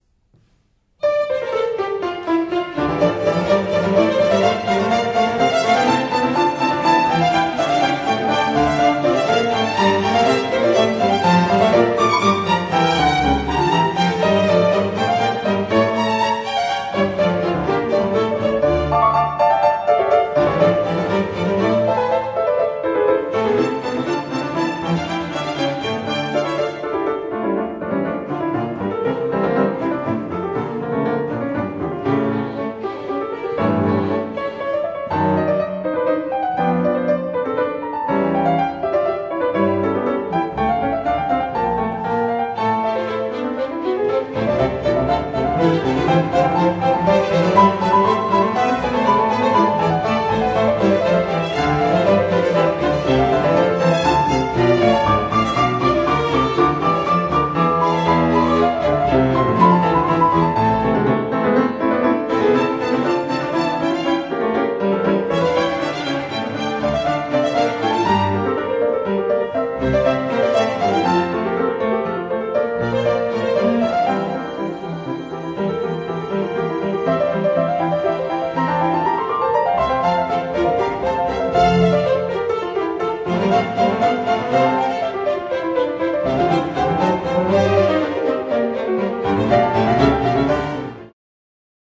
ピアノ
Dec. 2014　浜離宮朝日ホールでのライヴ録音
Live Recording at Hamarikyu Asahi Hall in Tokyo, Japan